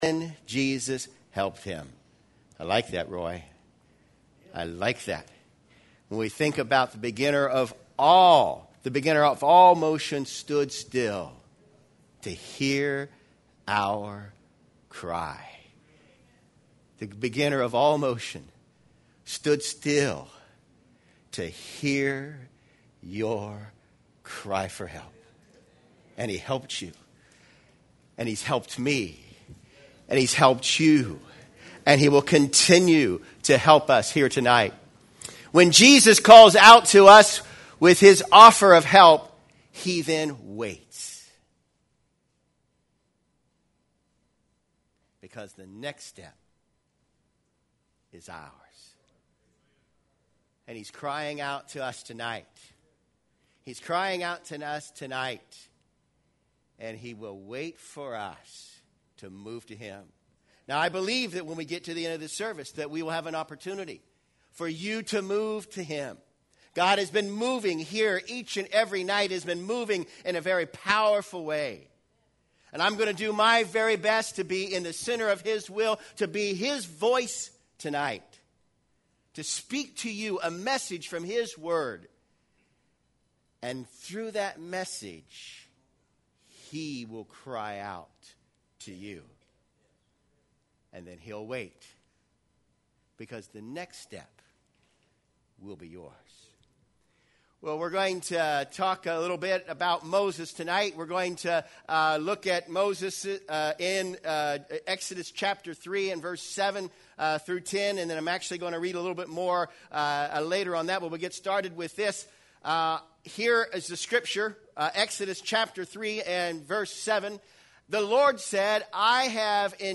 Sermons from the 2015 Taylor County Campmeeting - Taylor County Campmeeting, Butler, GA